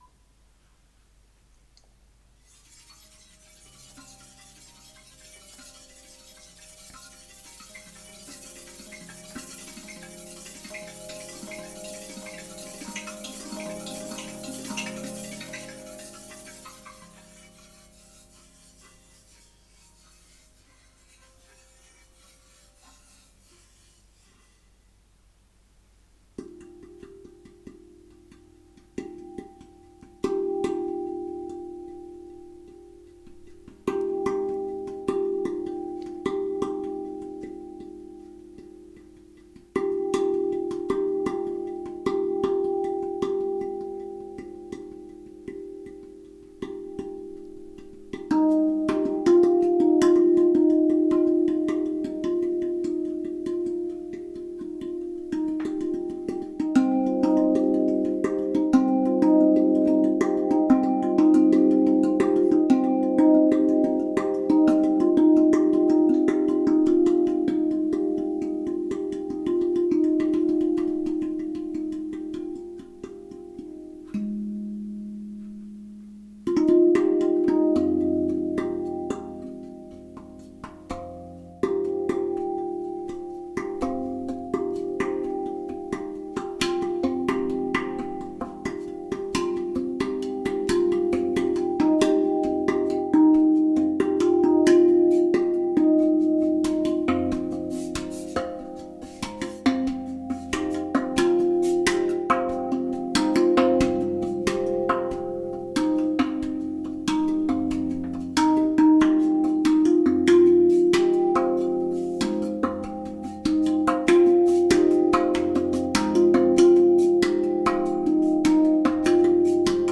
I had the chance to play it on a tongue drum in a place that is very special to me. During the playing there were moments where the music seemed to carry itself, and I could feel a very beautiful energy arising.